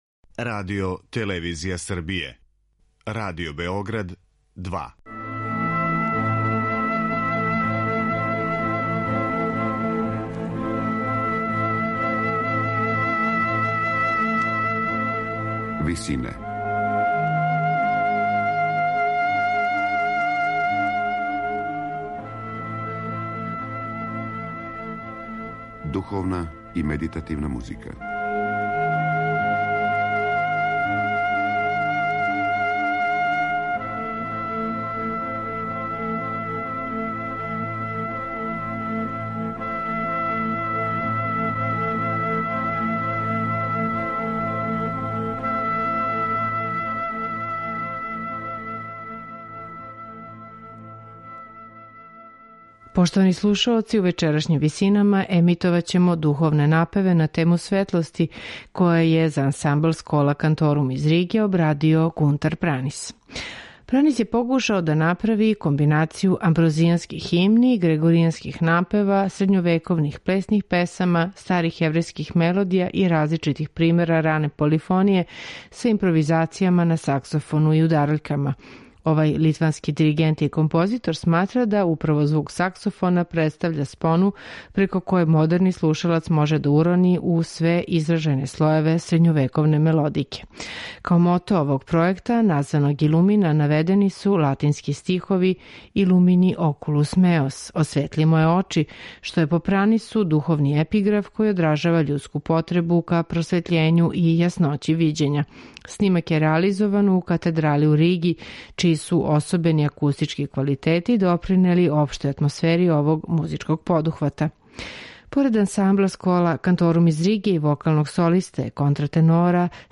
сопран
саксофониста
инструментални солисти